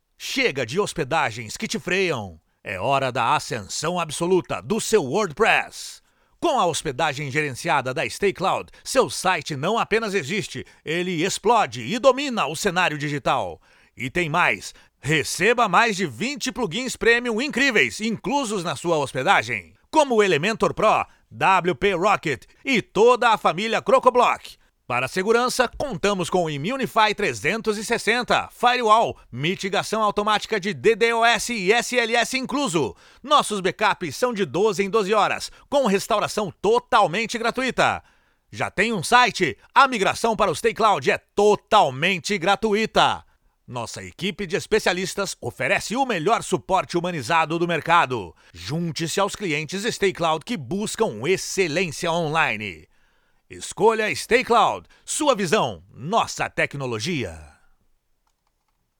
Enviei em anexo o audio que o locutor gravou.
StayCloud = isteiclaudi
WordPress = uordiprés
Backup = bêcapi